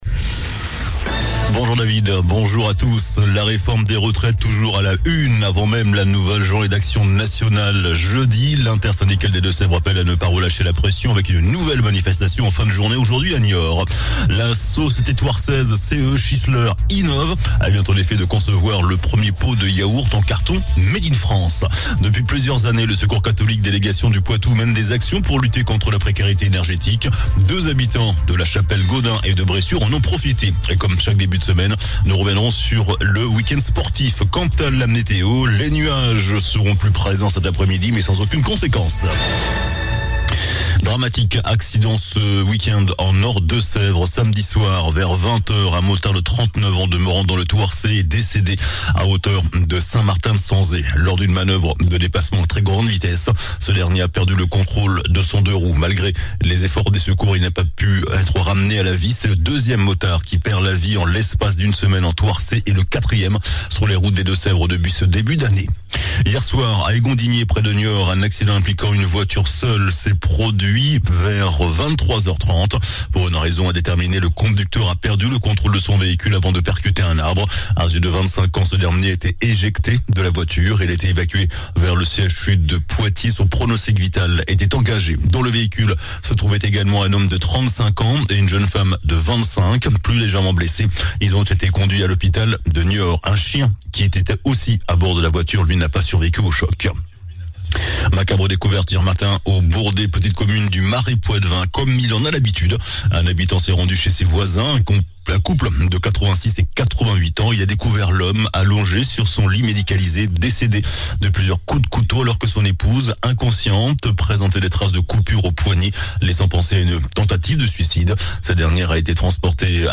JOURNAL DU LUNDI 20 MARS ( MIDI )